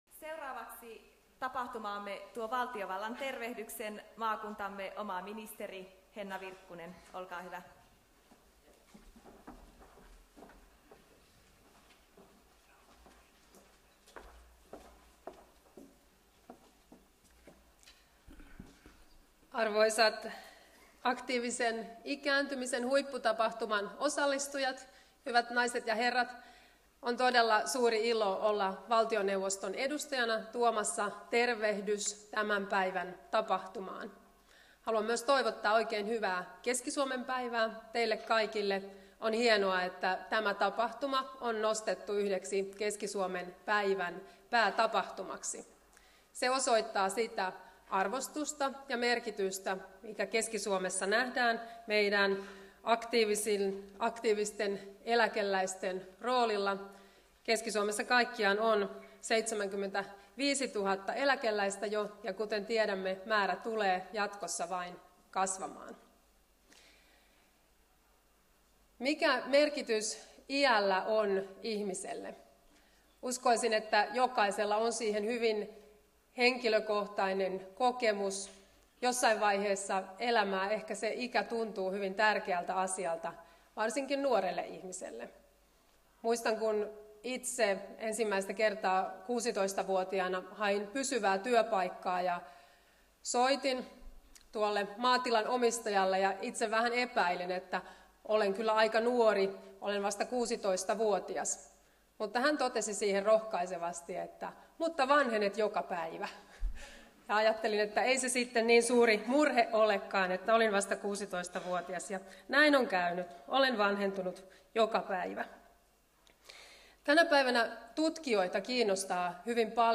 2. Valtiovallan tervehdys 8426